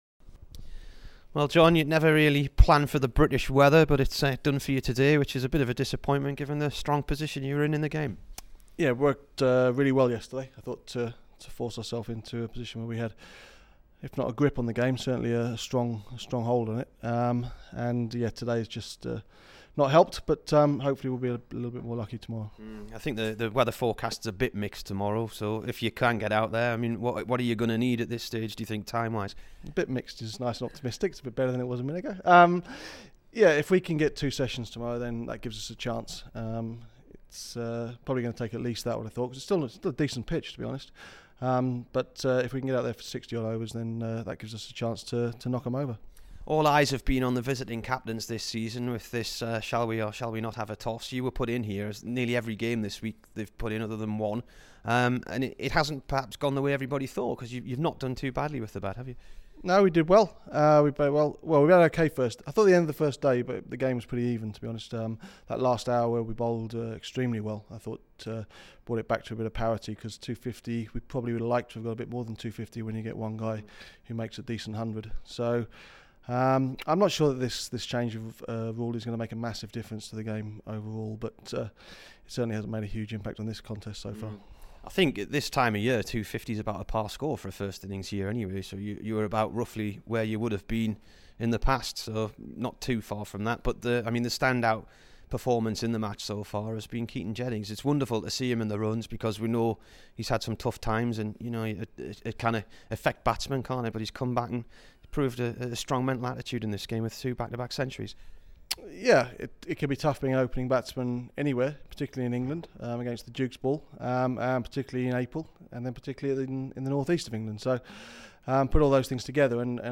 THE DURHAM COACH TALKS TO BBC NEWCASTLE FOLLOWING A WASHED OUT DAY 3 V SOMERSET